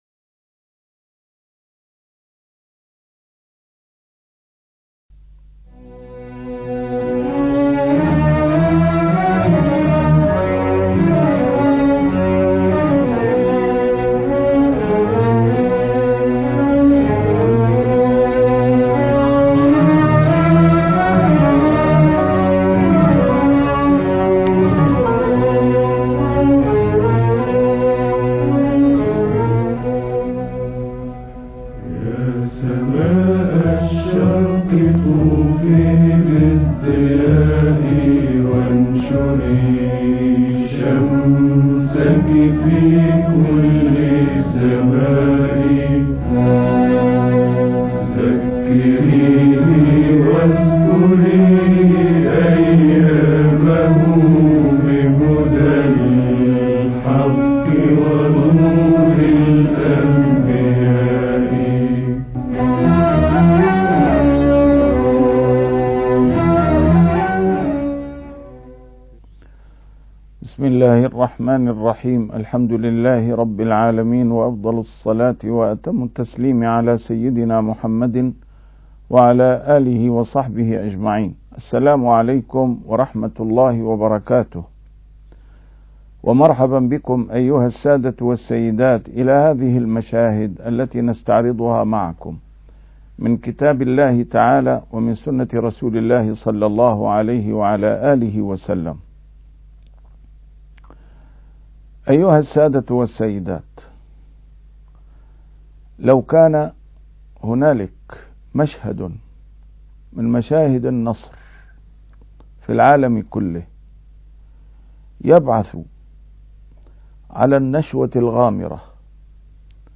A MARTYR SCHOLAR: IMAM MUHAMMAD SAEED RAMADAN AL-BOUTI - الدروس العلمية - مشاهد وعبر من القرآن والسنة - 29- رسول الله صلى الله عليه وسلم عند فتح مكة